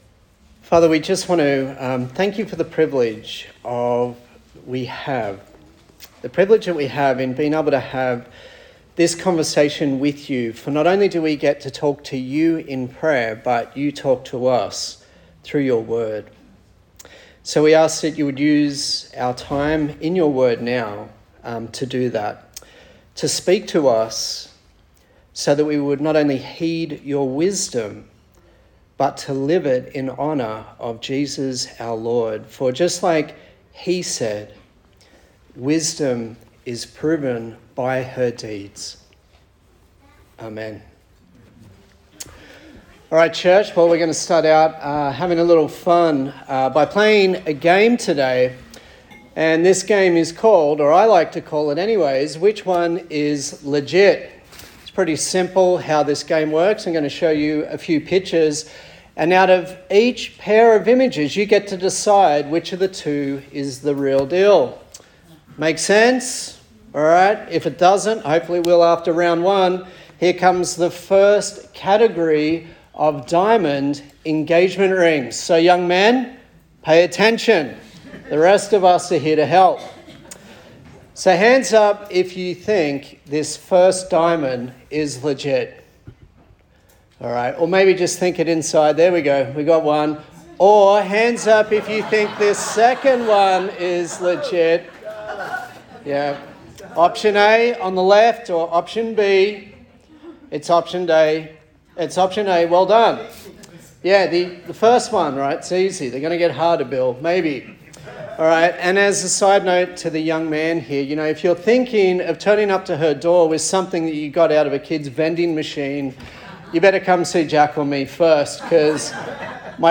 Service Type: Sunday Service A sermon on the book of James